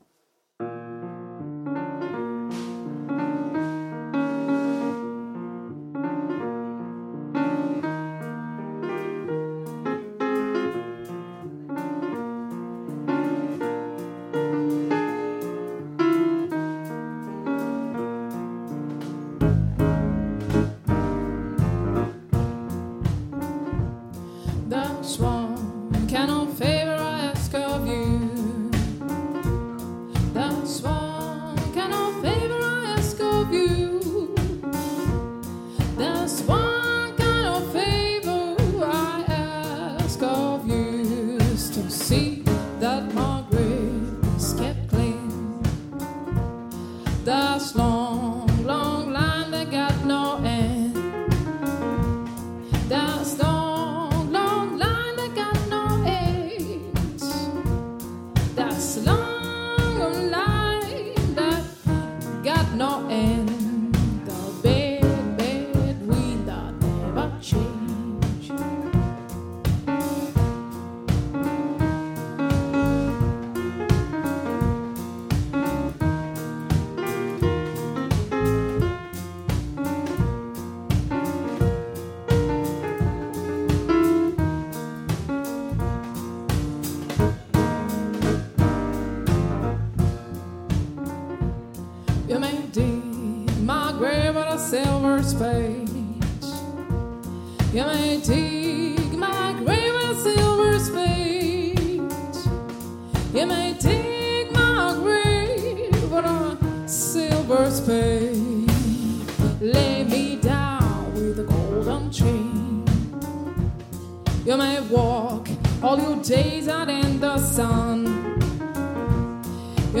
группа / Москва / блюз / джаз